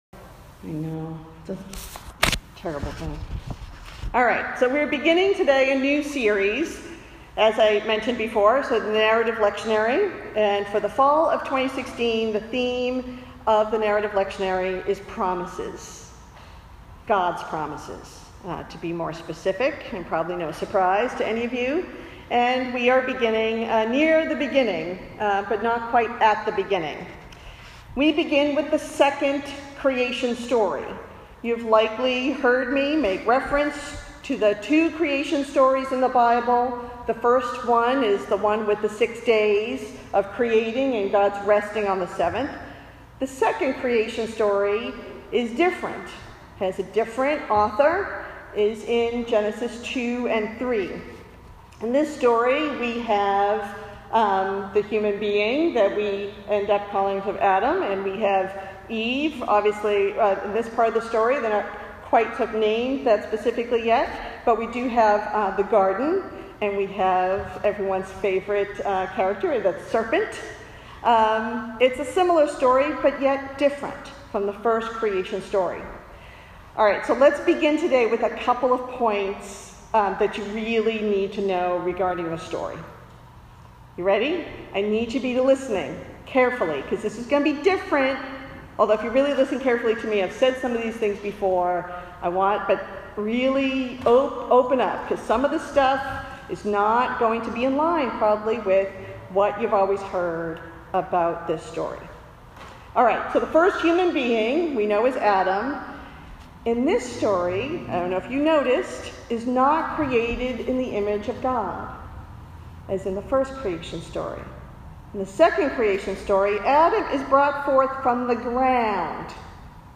Related Posted in Sermons (not recent)